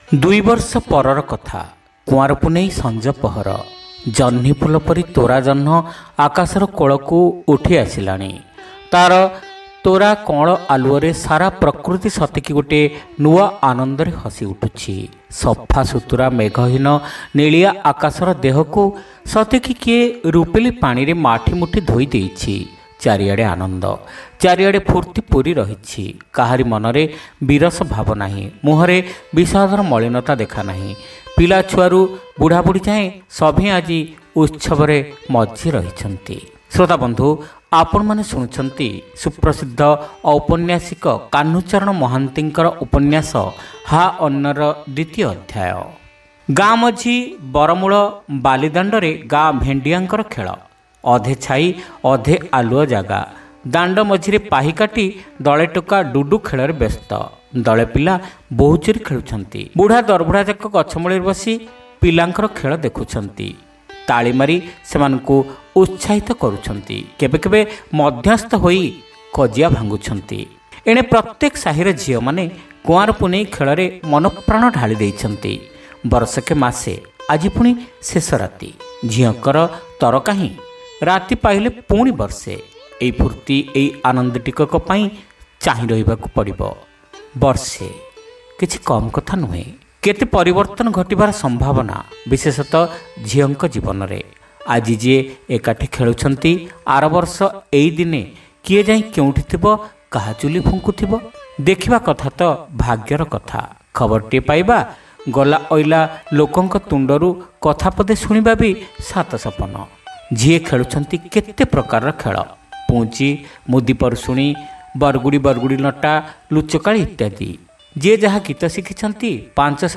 ଶ୍ରାବ୍ୟ ଉପନ୍ୟାସ : ହା ଅନ୍ନ (ଦ୍ୱିତୀୟ ଭାଗ)